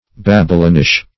Babylonish \Bab"y*lo`nish\, a.